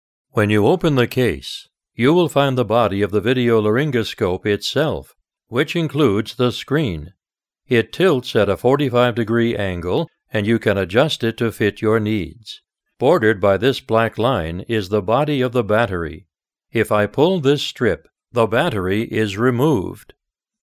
Voiceovers American English male voice overs. Group A